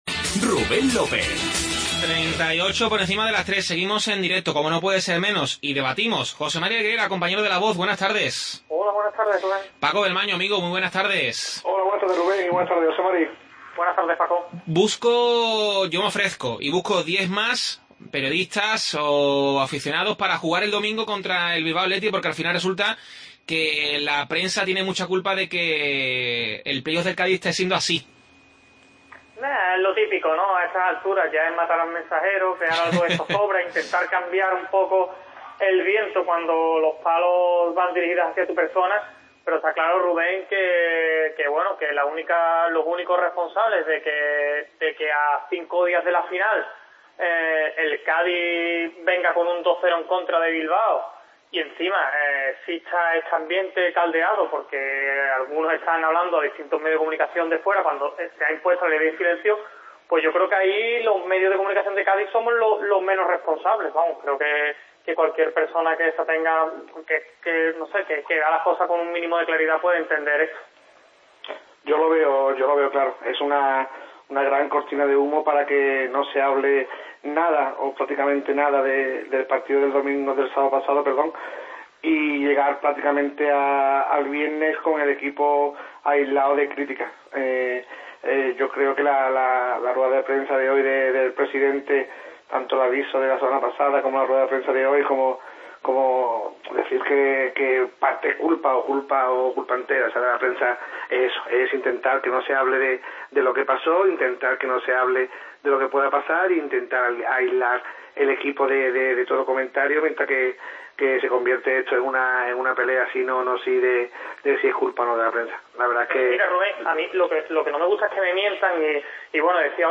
Vivimos el ambiente del Bilbao Athletic vs Cádiz en las calles de la ciudad vasca con ambas aficiones